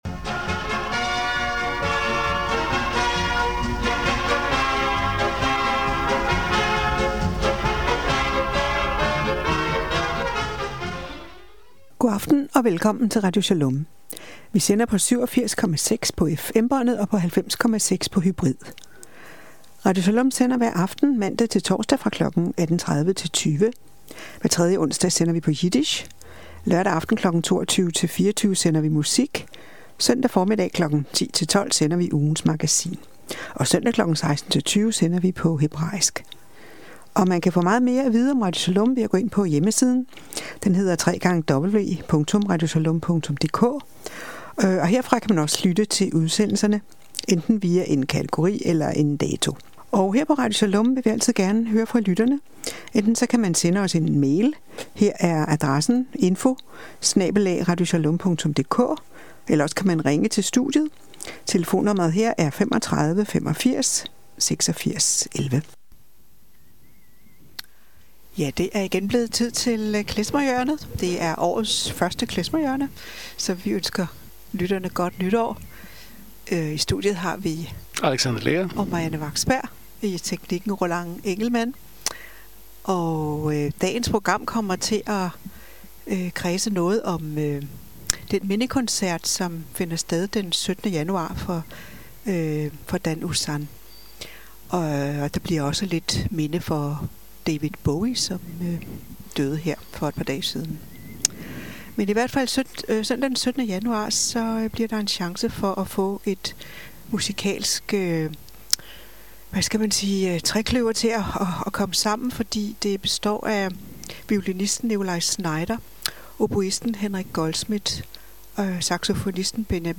Klezmerhjørnet.